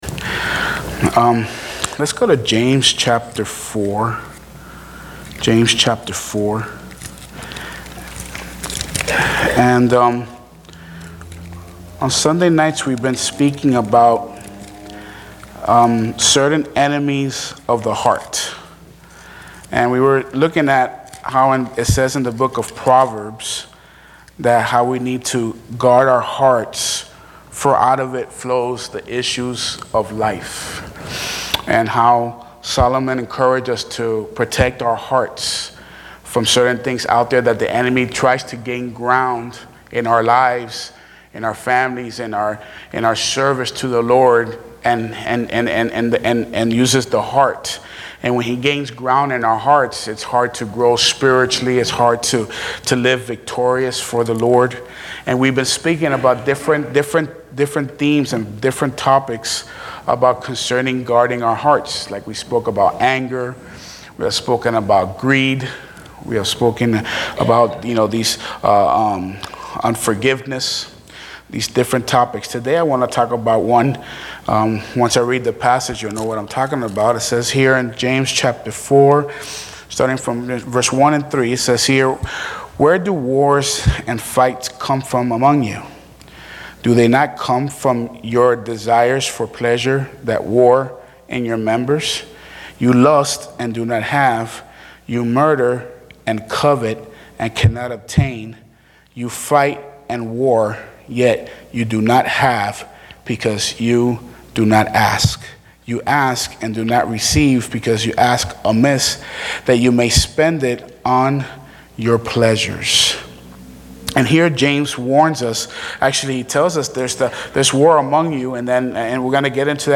Sermons - Buena Vista Baptist